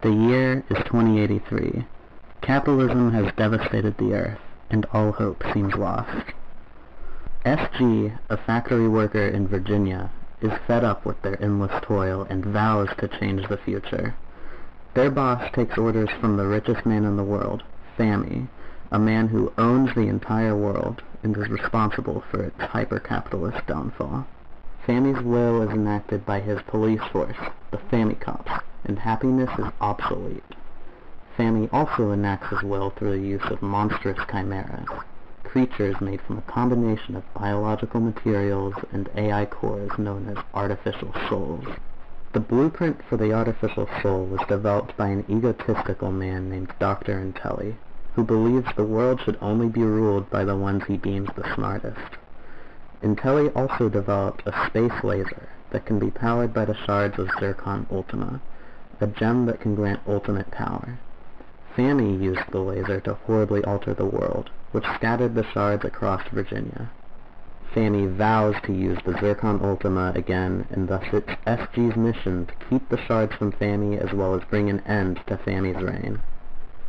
intro_voiceover.ogg